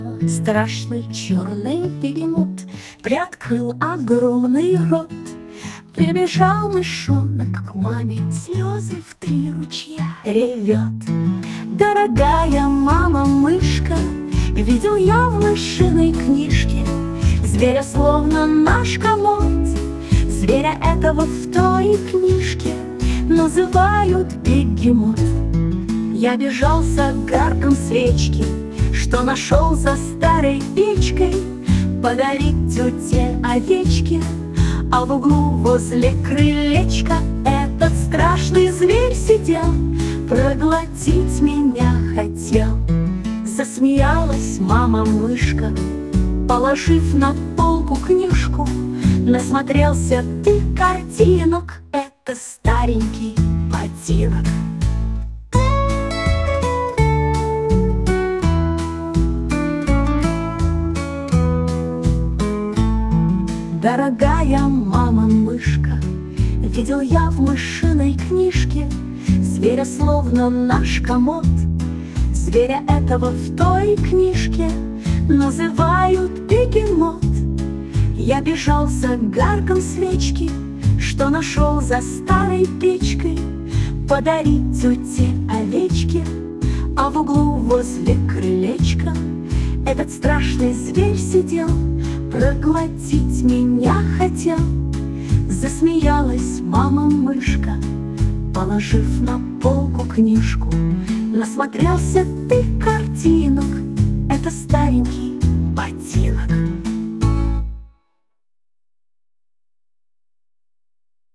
Детская